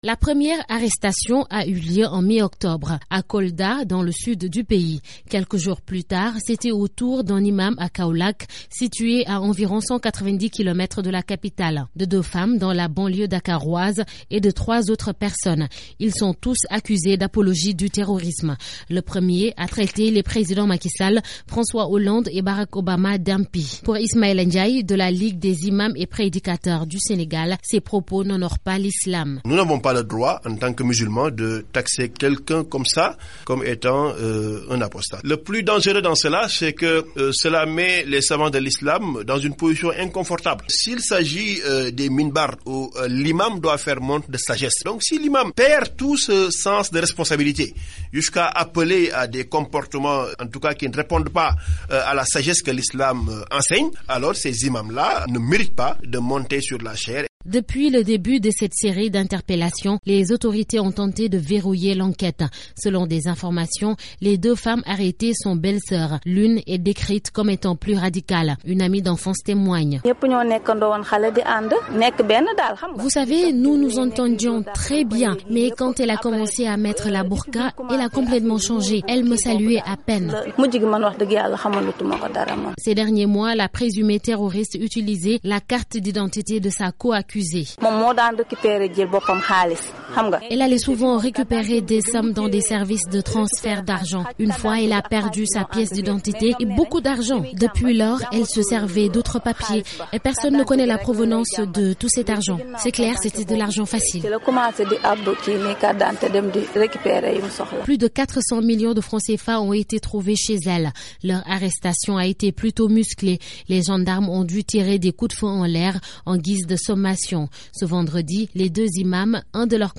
Correspondance